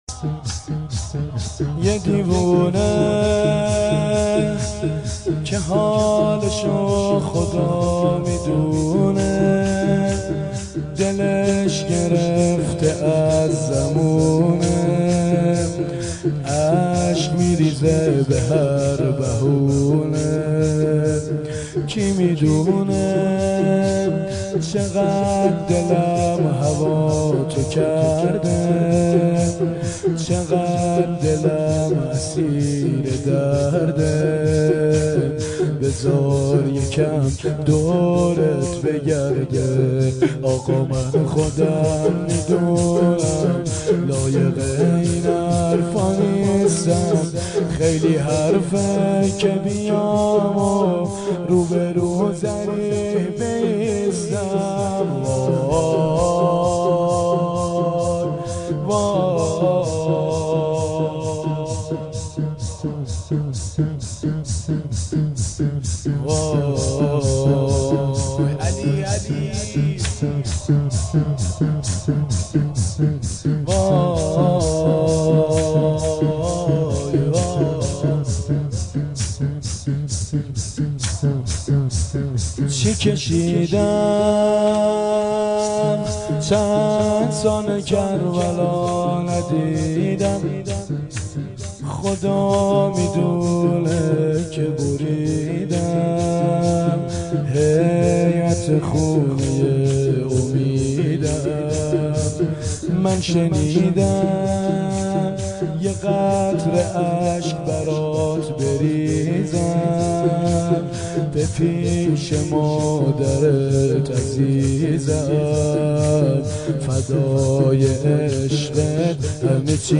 شور
هیئت عاشقان ثارالله
شب هفتم محرم ۱۴۴۱